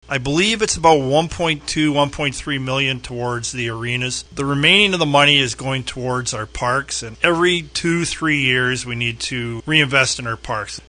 As part of the budget process, council dedicated over $2.2 million to recreational services, including those three areas. Mayor Colin Grantham expanded a bit on that during our Minute with the Mayor segment this week.